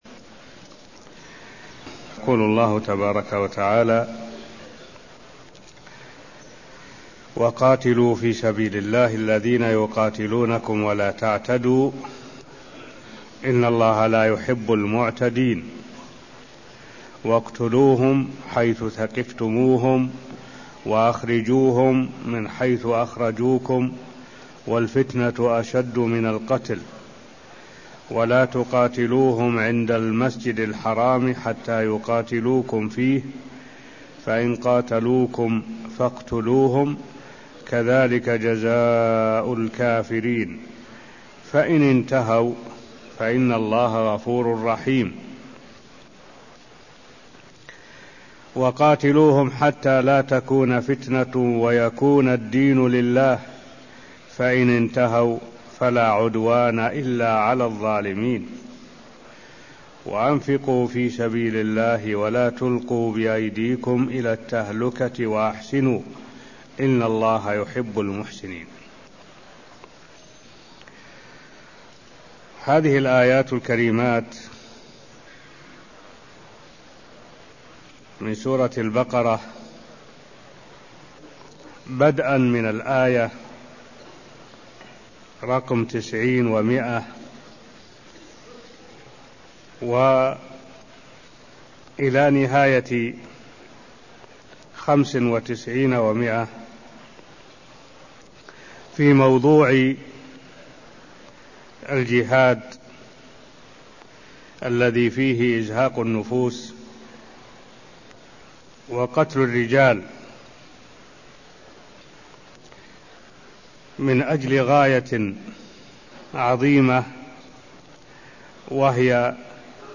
المكان: المسجد النبوي الشيخ: معالي الشيخ الدكتور صالح بن عبد الله العبود معالي الشيخ الدكتور صالح بن عبد الله العبود تفسير الآيات190ـ195 من سورة البقرة (0095)